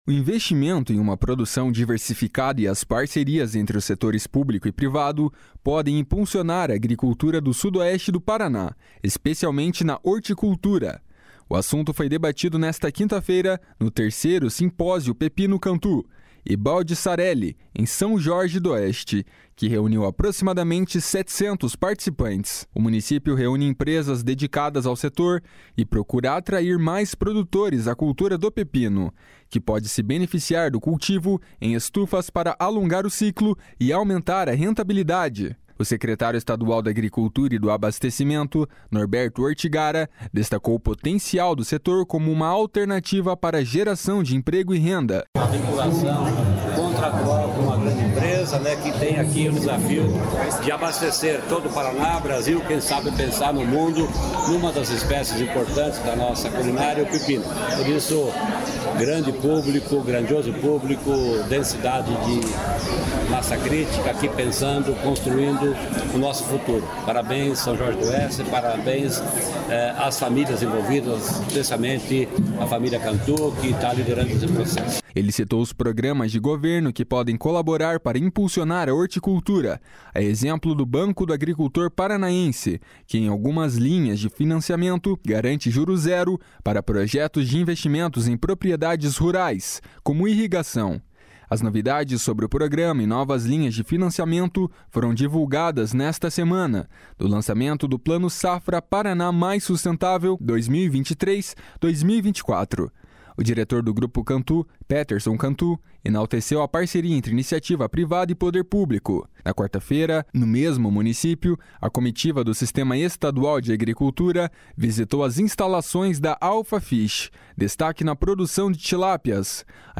O secretário estadual da Agricultura e do Abastecimento, Norberto Ortigara, destacou o potencial do setor como uma alternativa para geração de emprego e renda. // SONORA NORBERTO ORTIGARA //